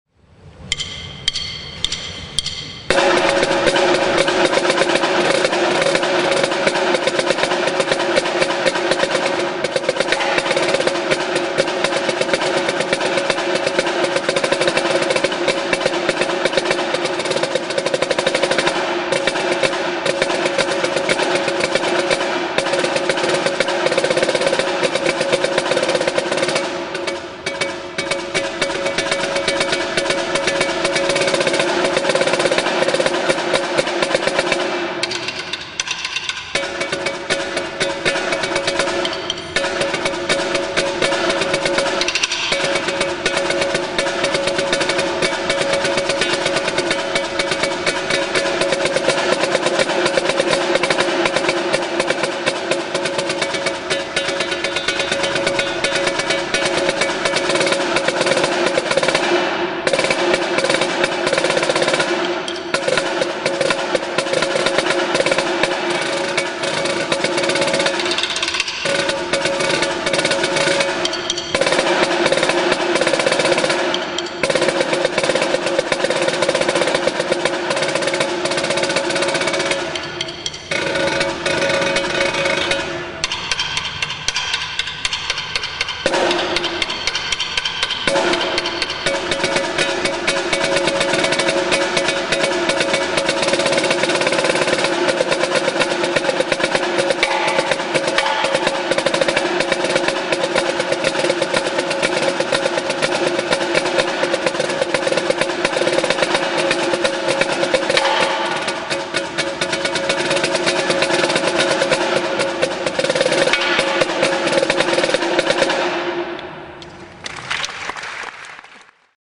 Voicing: Snare Drum